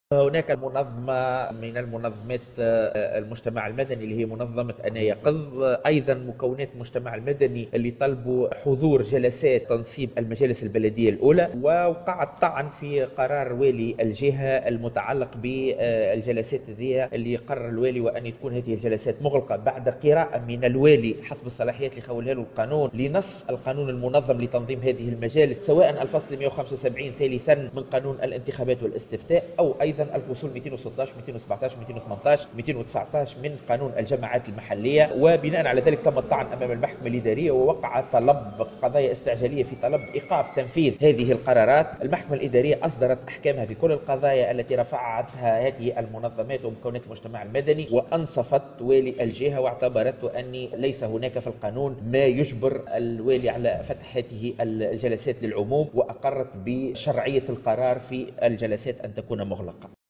وأكد والي المنستير أكرم السبري، في تصريح لمراسل الجوهرة أف أم، أن المحكمة...